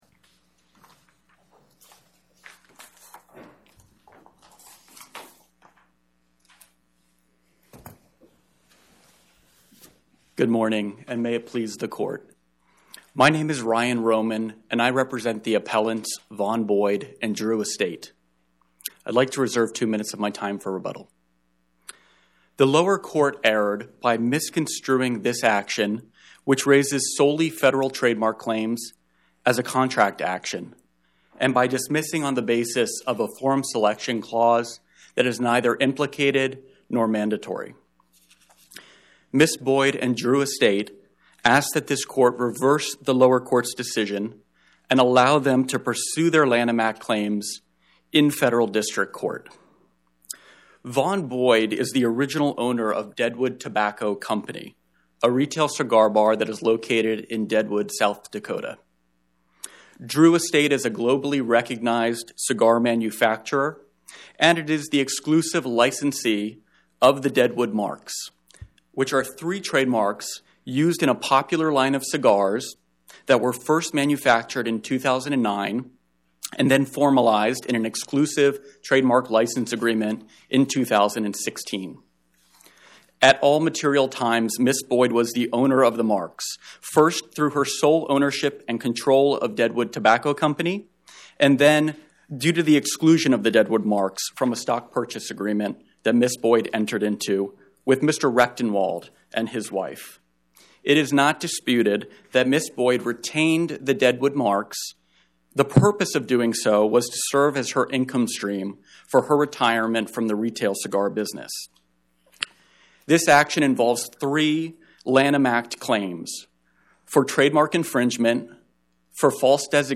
Oral argument argued before the Eighth Circuit U.S. Court of Appeals on or about 10/22/2025